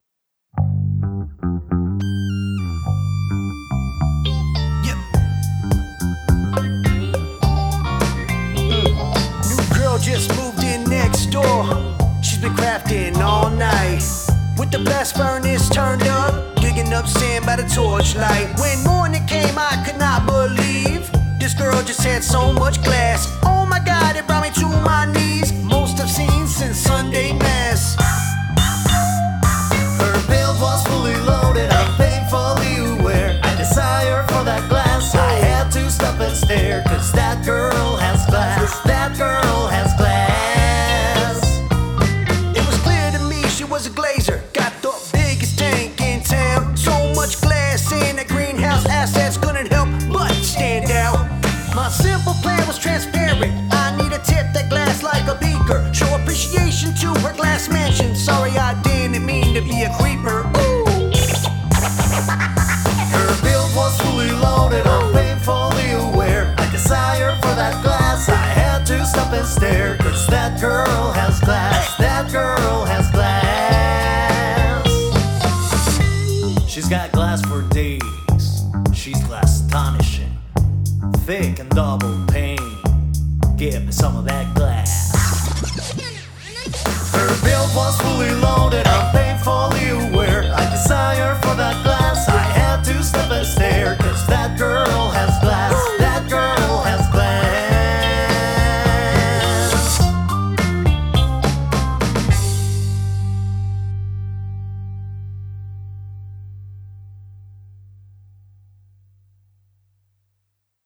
Verses in 4/4 time signature and the choruses in 3/4.
The flow isn’t always natural.